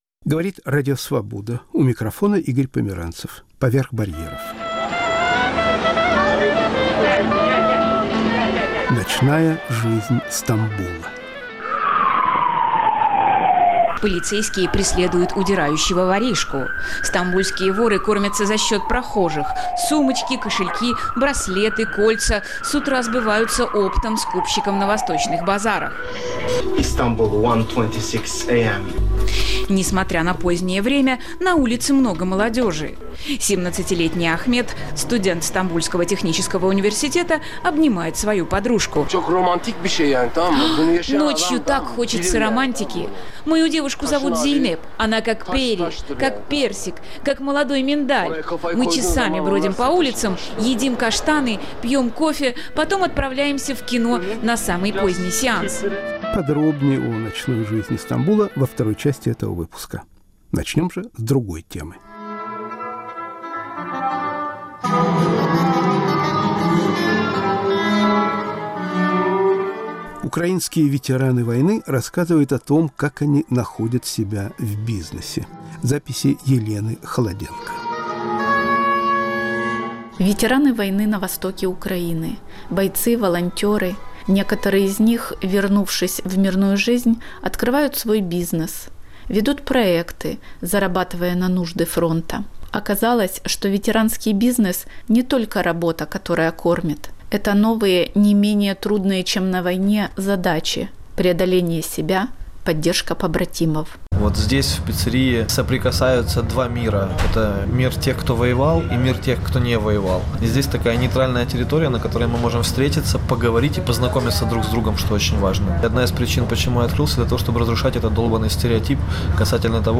Разговор с украинскими солдатами.*** Философия как путешествие.